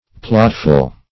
\Plot"ful\